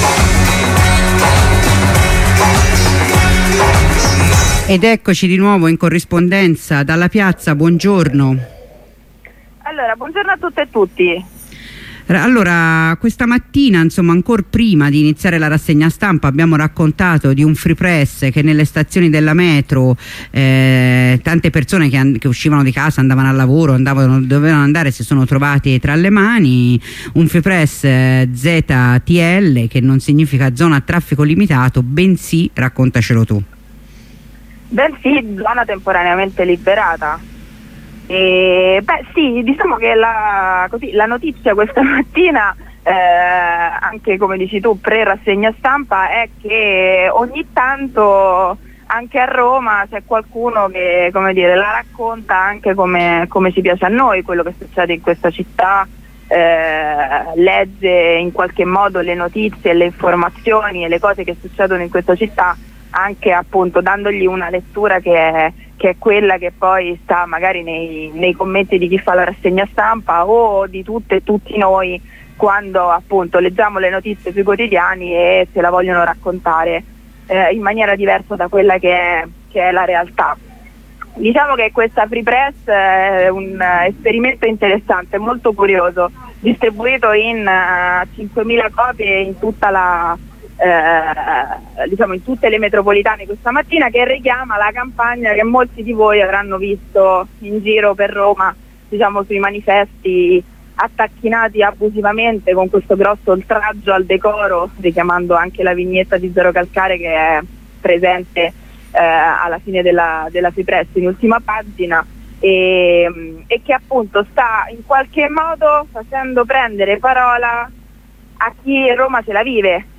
Dalle prime ore della mattina distribuzione nelle stazioni metro che apre Roma Comune tre giorni di lotta, la prima corrispondenza da una delle stazioni dove si effettua la distribuzione e poi una seconda corrispondenza per parlare più nel complesso dell'iniziativa Roma Comune tenendo occhi e orecchie aperte su quanto succederà nelle giornate del 19 e 20.